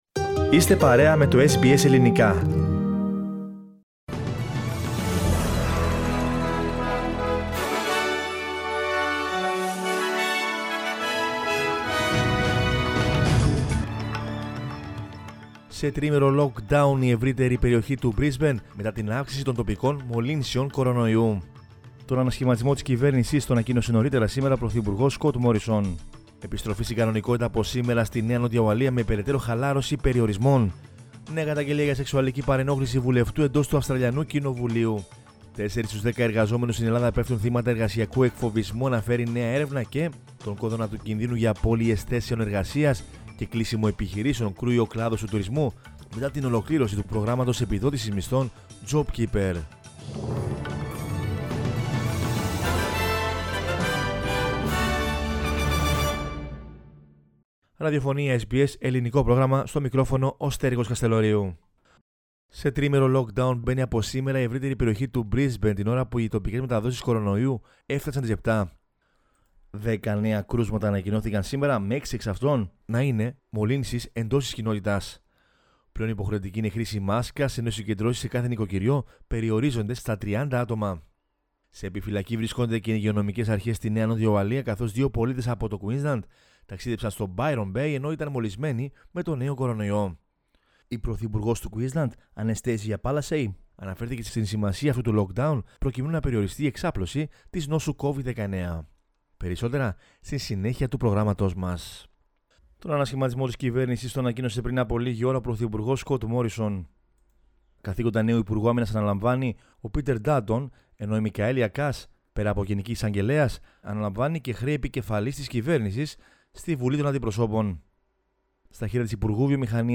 News in Greek from Australia, Greece, Cyprus and the world is the news bulletin of Monday 29 March 2021.